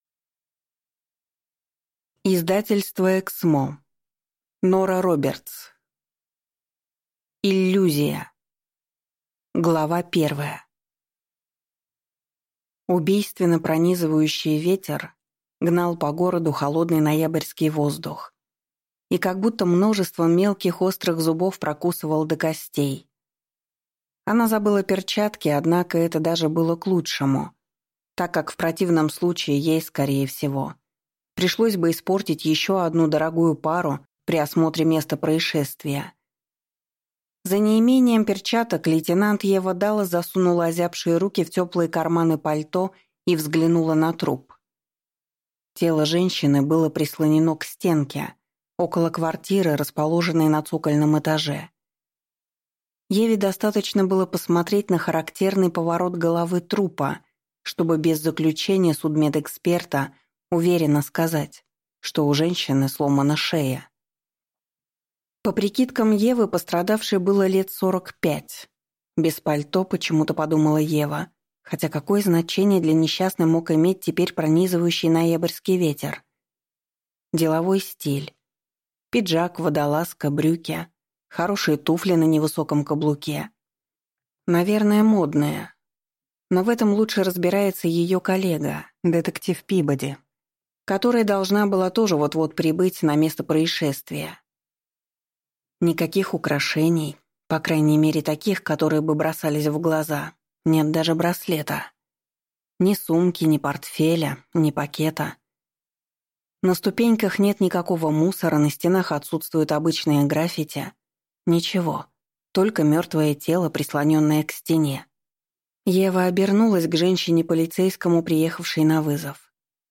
Аудиокнига Иллюзия | Библиотека аудиокниг